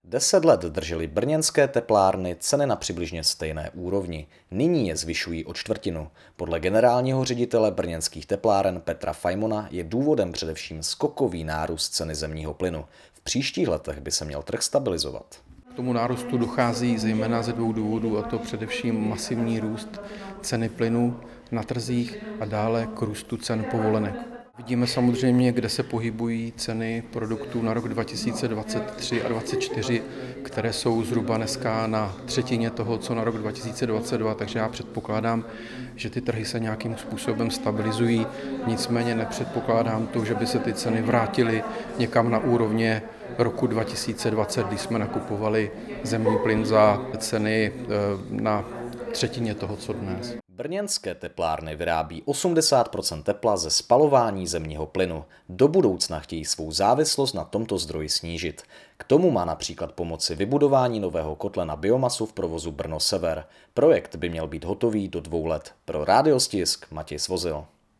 TK - Brněnské teplárny.wav